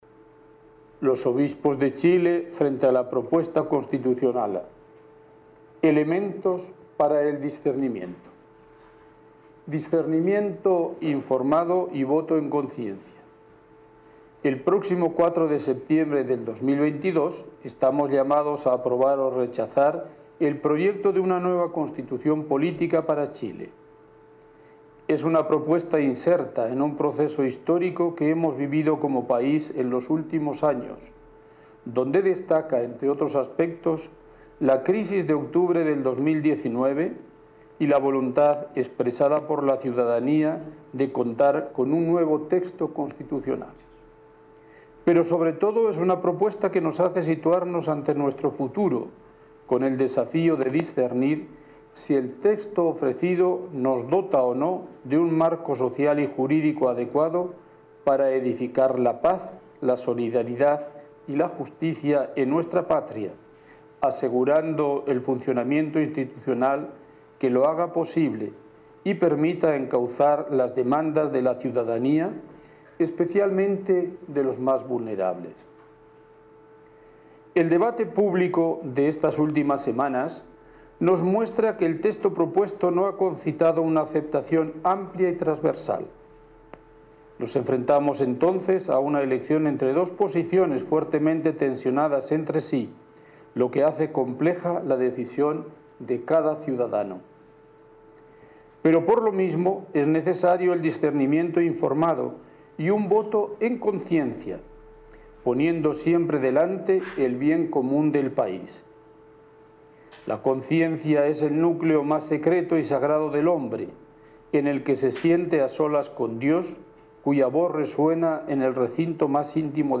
La declaración fue leída por el Cardenal Celestino Aós, presidente de la Conferencia Episcopal de Chile.